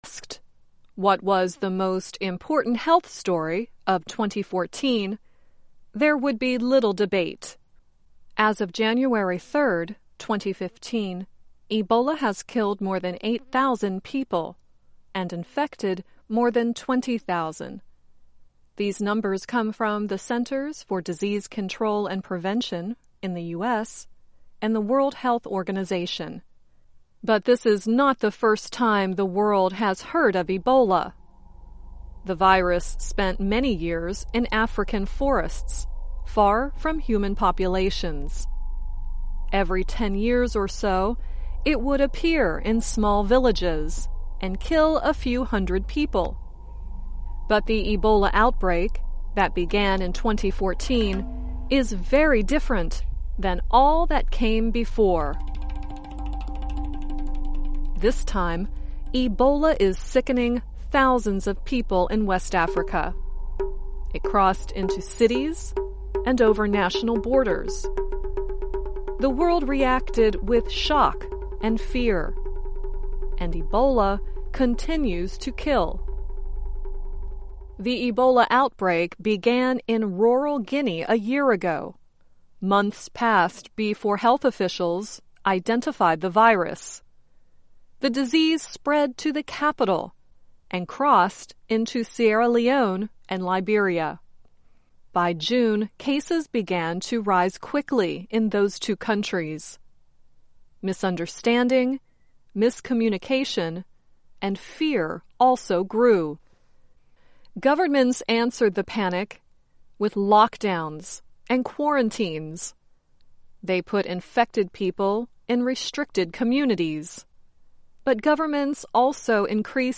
Learn English as you read and listen to a weekly show about developments in science, technology and medicine. Our stories are written at the intermediate and upper-beginner level and are read one-third slower than regular VOA English.